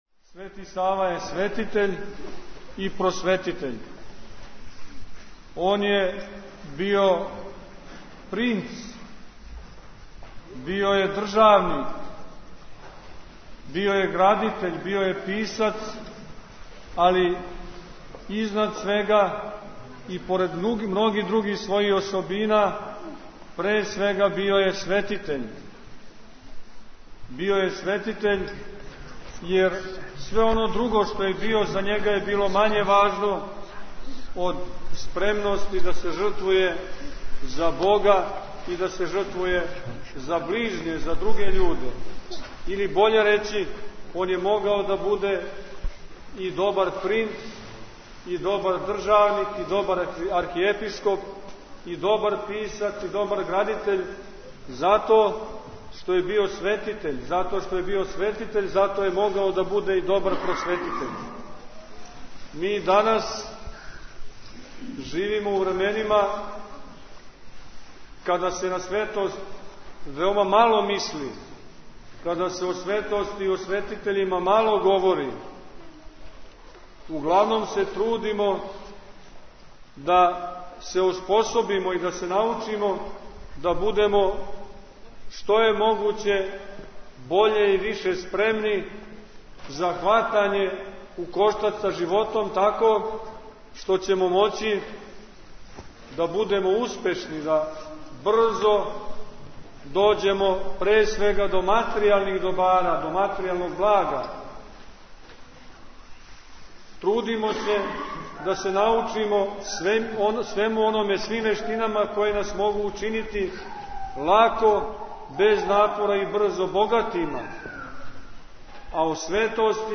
Звучни запис беседе владике Порфирија).